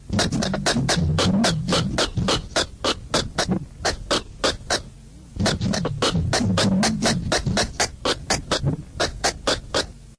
快速攻击音效
描述：快速攻击音效。
标签： 科幻 歪曲 快速 翘曲 攻击 抽象 sfx 运动 瞬移
声道立体声